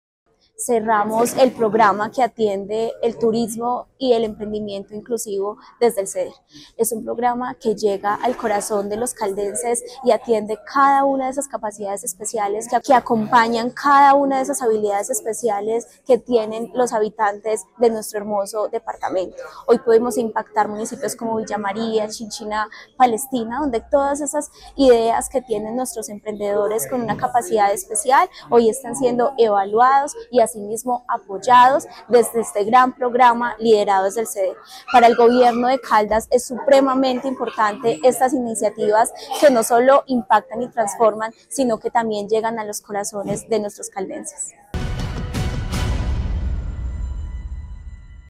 Tania Echeverry Rivera, secretaria de Desarrollo, Empleo e Innovación.